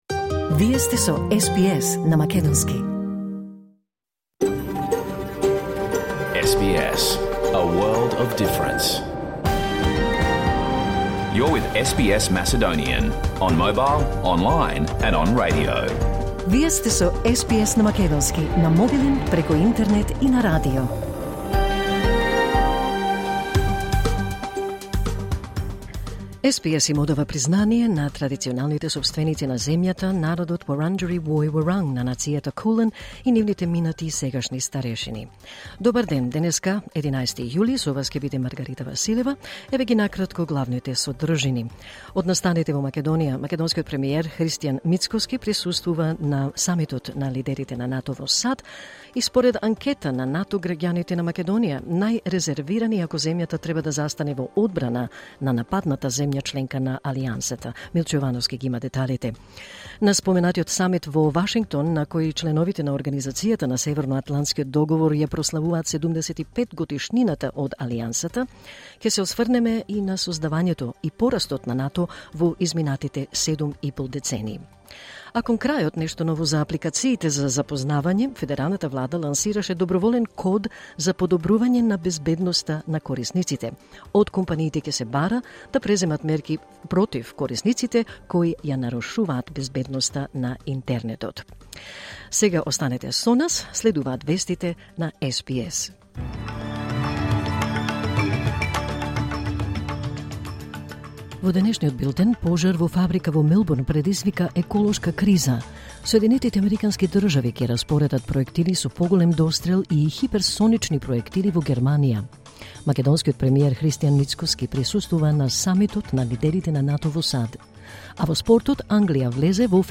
SBS Macedonian Program Live on Air 11 July 2024